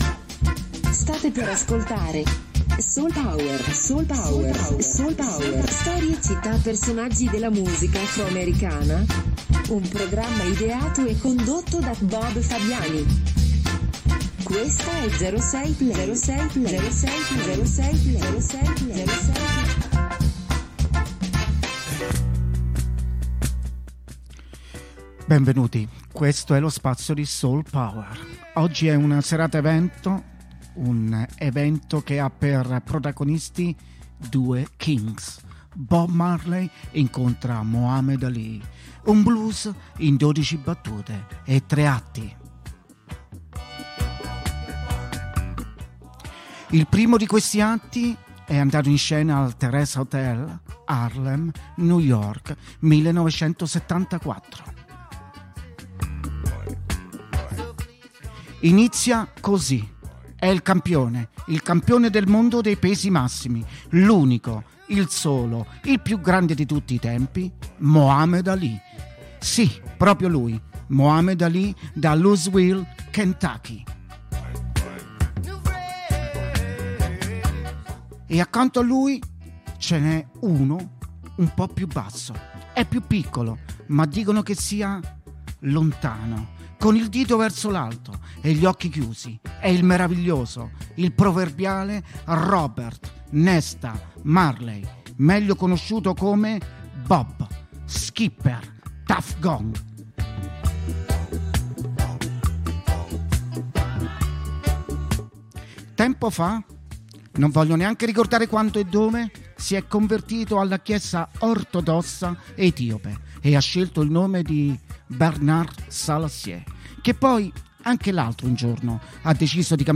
A tutto Reggae!